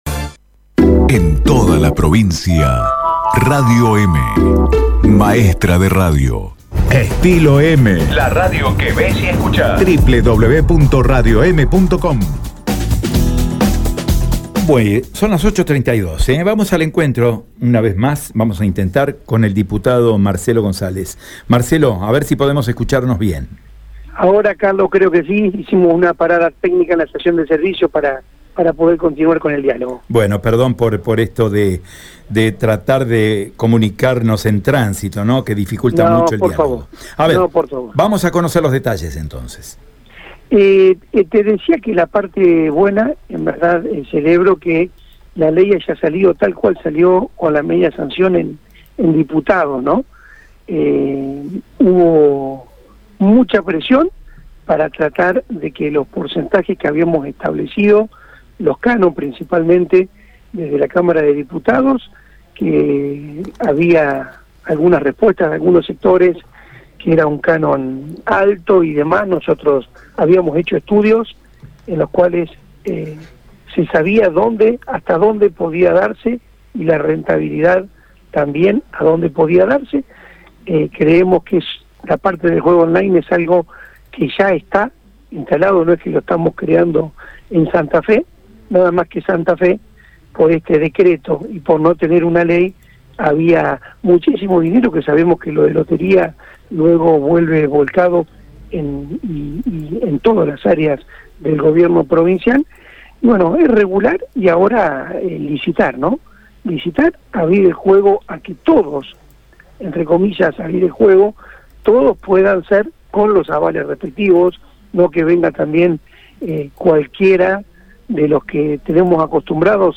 "La idea es regular y que sea una fuente de ingresos genuinos", detalló el diputado provincial Marcelo González en Radio EME.
Escuchá la palabra de Marcelo González: